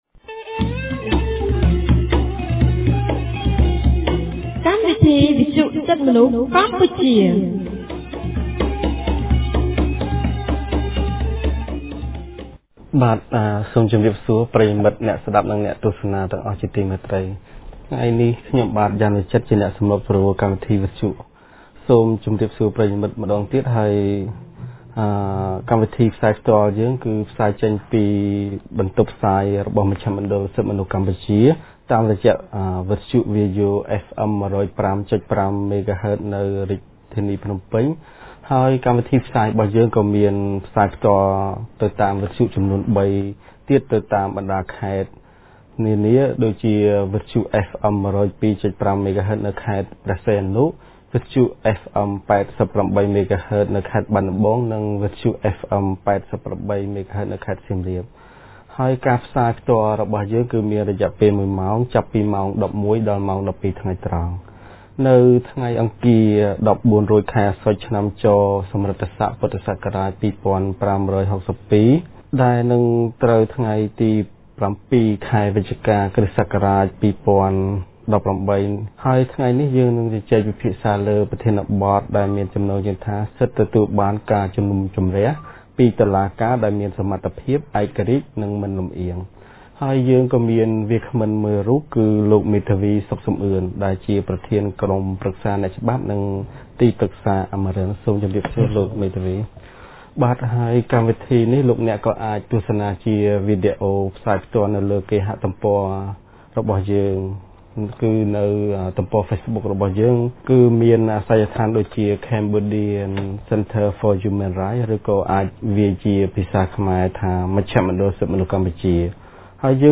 On 07 November 2018, CCHR’s Fair Trial Rights Project (FTRP) held a radio program with a topic on Right to be tried by an independent and impartial tribunal.